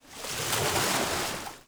SPLASH_Movement_03_mono.wav